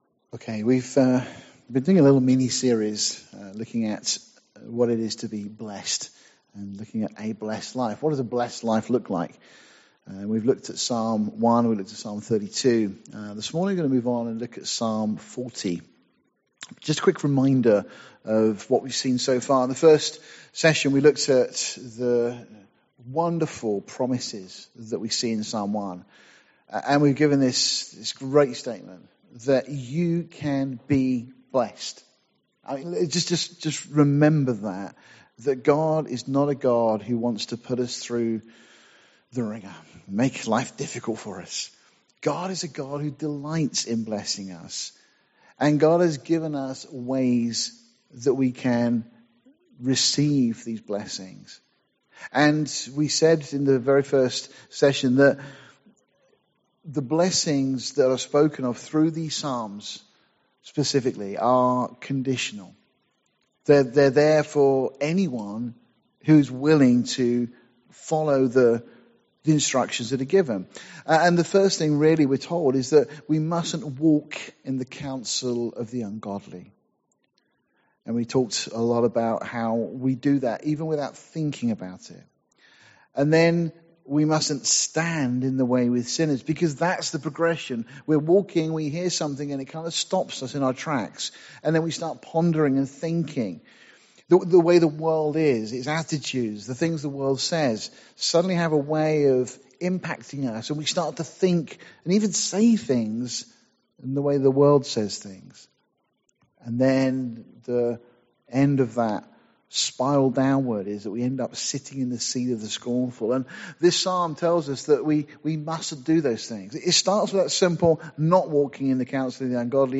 Series: A Blesssed Life , Sunday morning studies , Topical Studies Tagged with topical studies